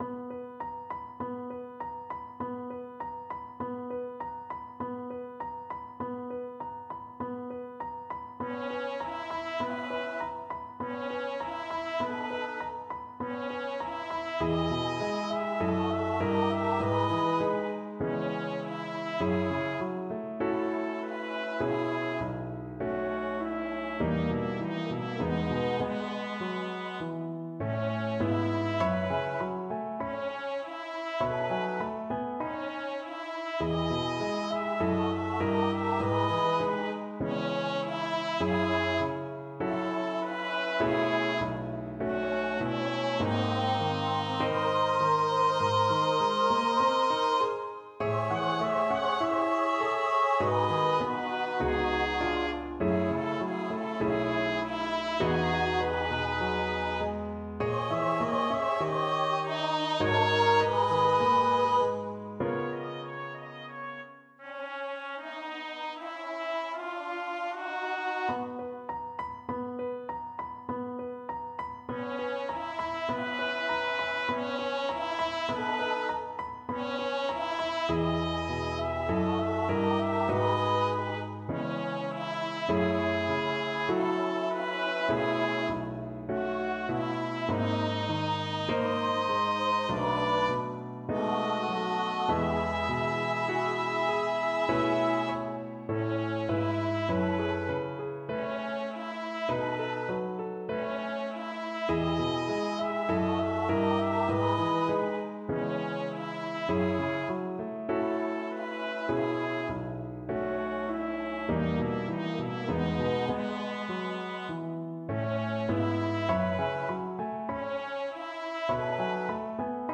00_wonka-Pure-Imagination-vs-for-rehearsal-s1_unknown.mp3